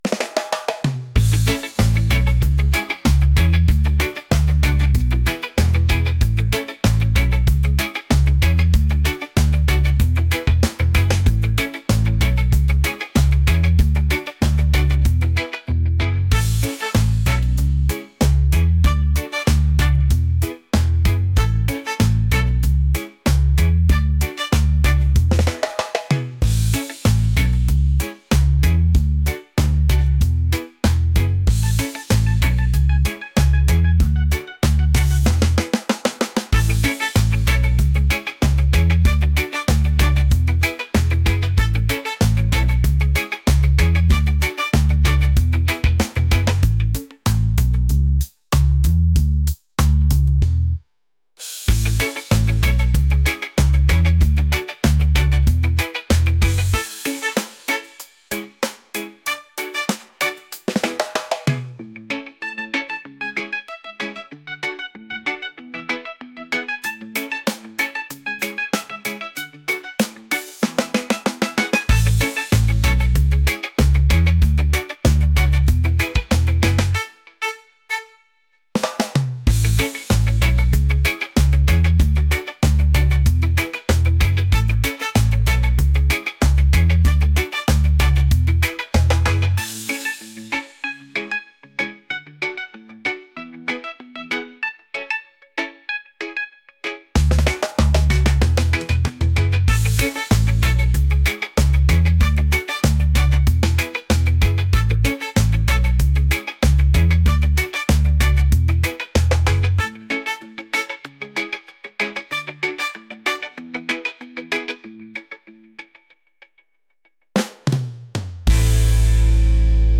upbeat | catchy | reggae